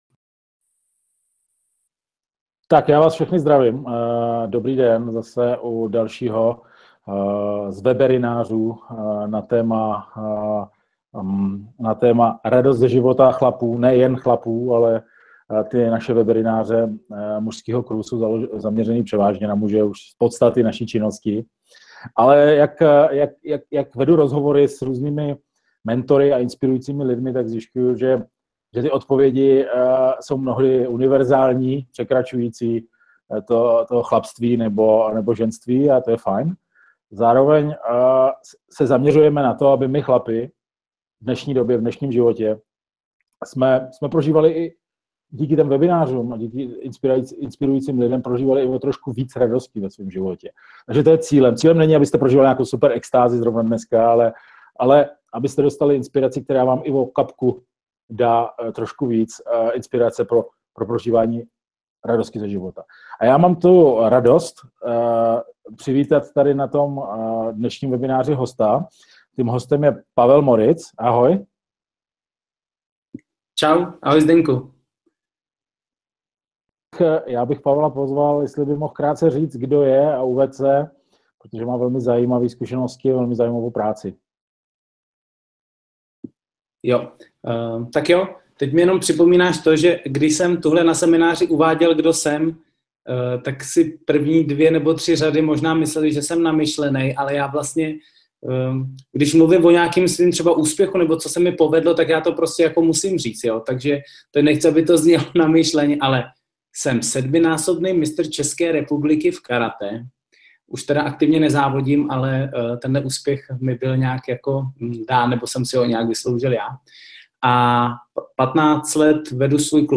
Více a mnoho dalšího v tomto rozhovoru.